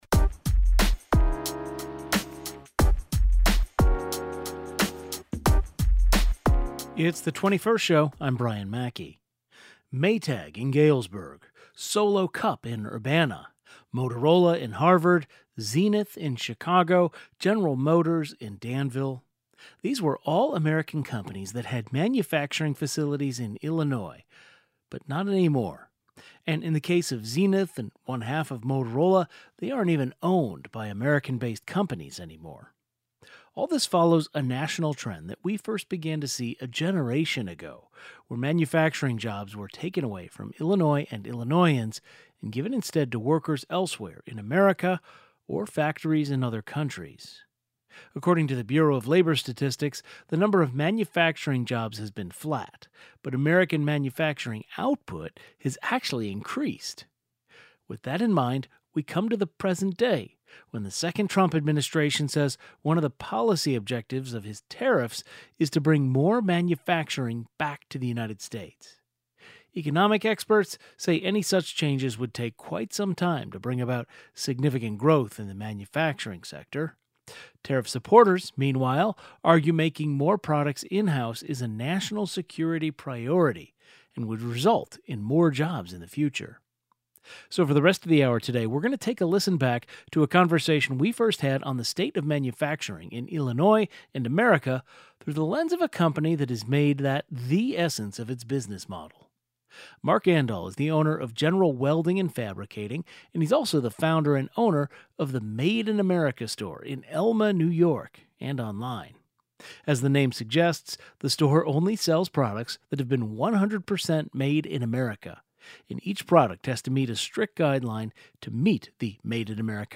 Business owner discusses decision to sell products only ‘Made in America’